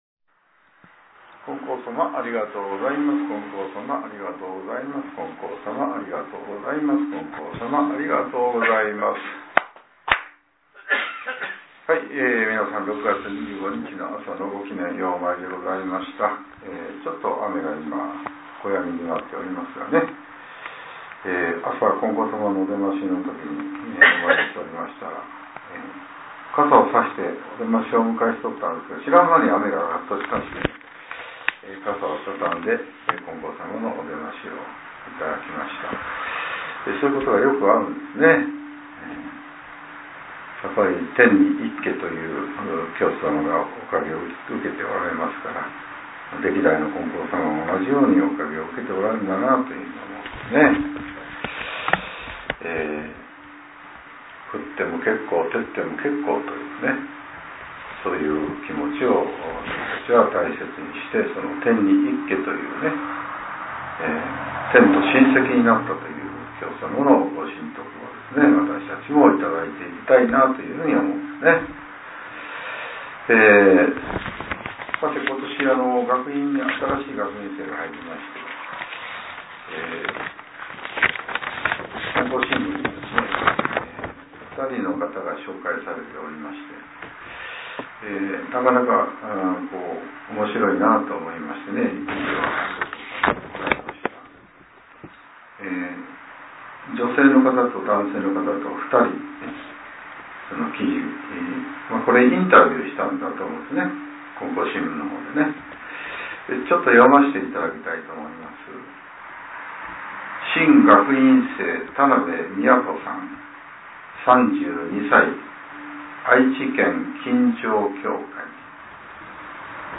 令和７年６月２５日（朝）のお話が、音声ブログとして更新されています。